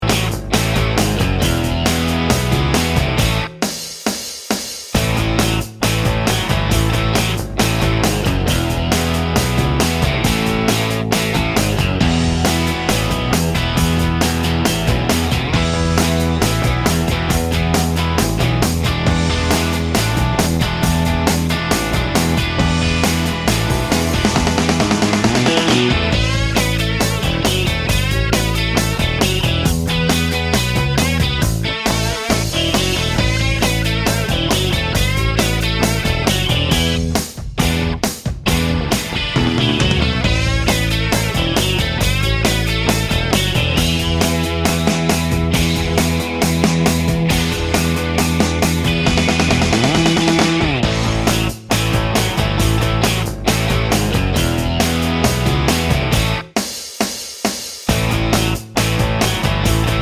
no Backing Vocals Rock 2:44 Buy £1.50